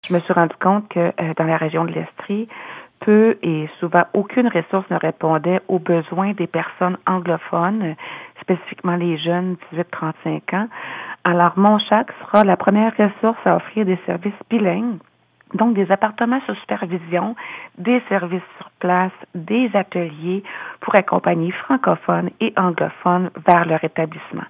Hablando con ella por téléfono